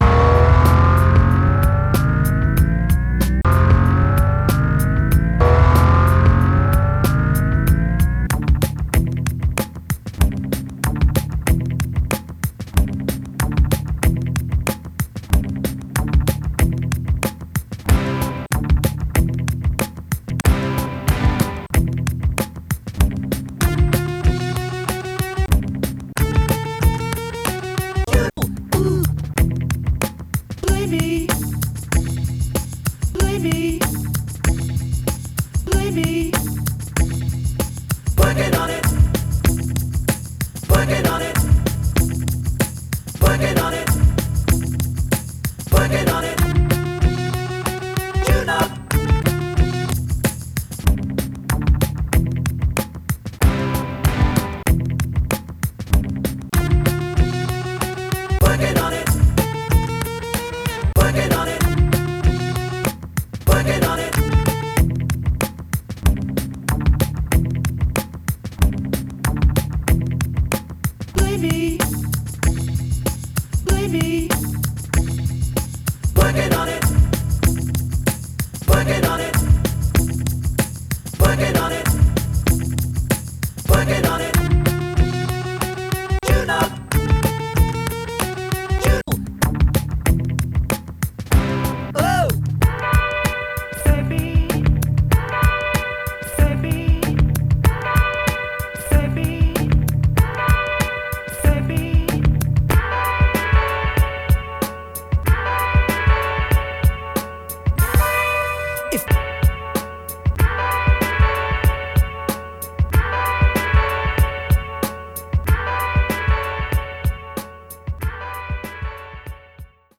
※BPM 77 -> 93 にテンポアップ、ピッチはそのまま
チルな感じのギターコード 2 ストローク